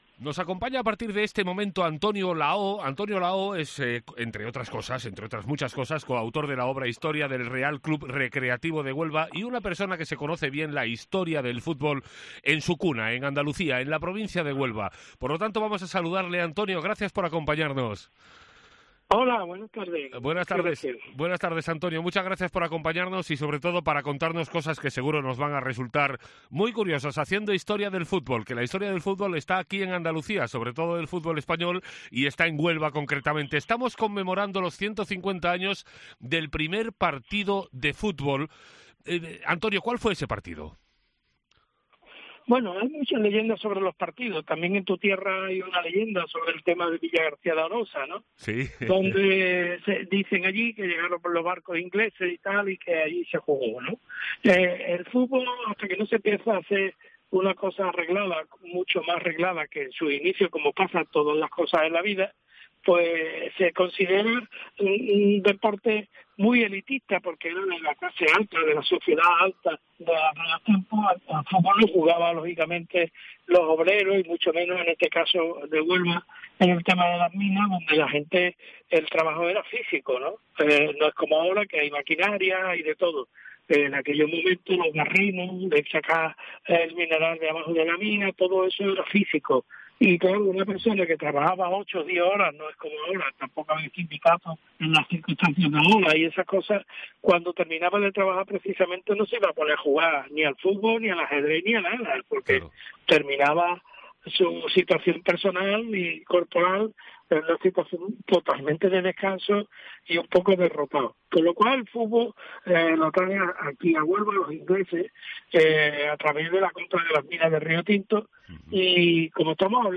De lo que no cabe ninguna duda es de que el Recreativo de Huelva es el equipo decano del fútbol español. Son solo algunas de las curiosidades que puedes descubrir en la entrevista que hemos mantenido